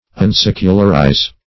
Search Result for " unsecularize" : The Collaborative International Dictionary of English v.0.48: Unsecularize \Un*sec"u*lar*ize\, v. t. [1st pref. un- + secularize.] To cause to become not secular; to detach from secular things; to alienate from the world.